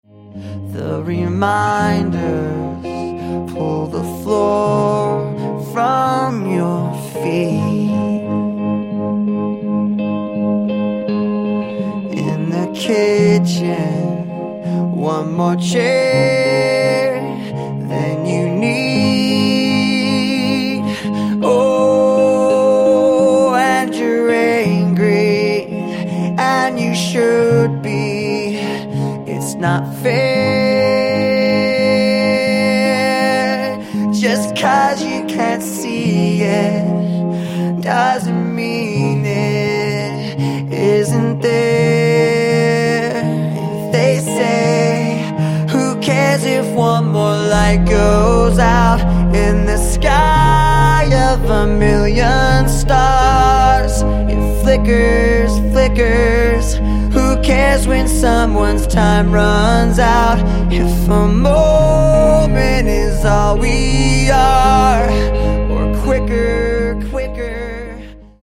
• Качество: 128, Stereo
поп
мужской вокал
грустные
ballads
печальные
Chill
Melodic
лиричные
romantic
vocal